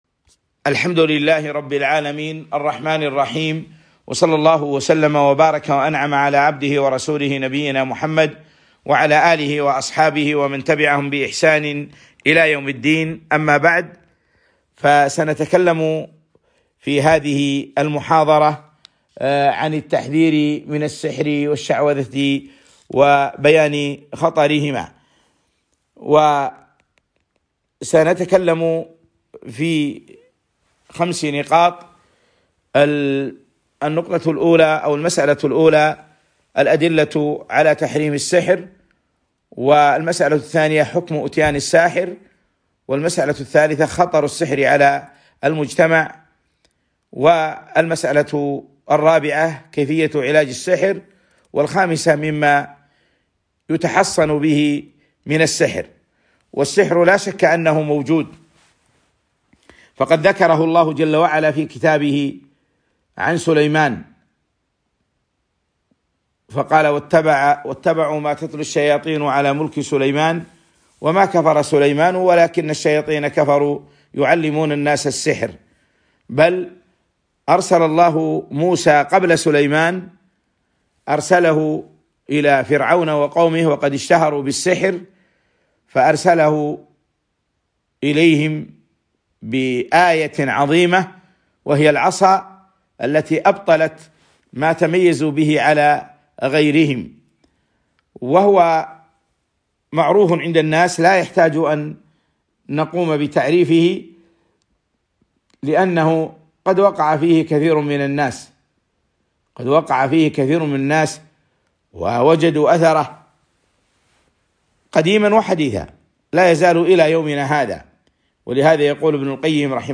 محاضرة - تحصين الإنسان من مكائد الشيطان